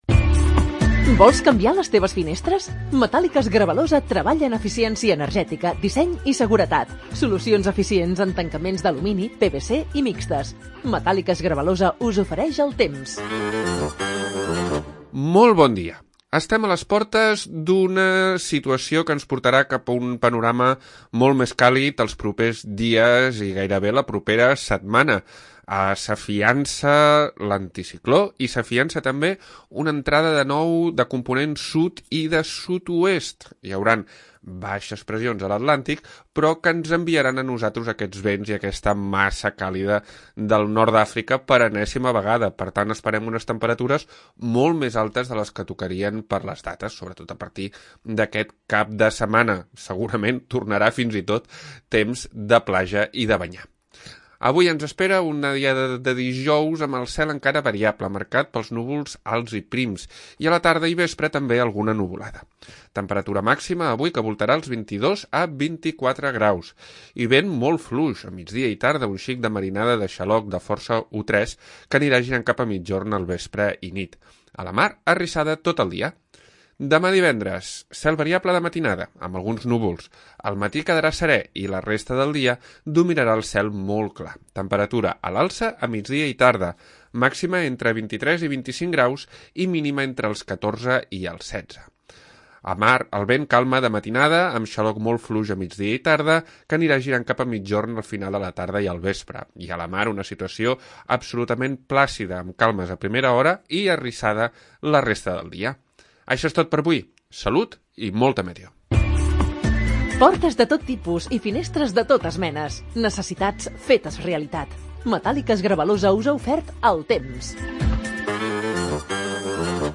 Previsió meteorològica 13 d'Octubre de 2022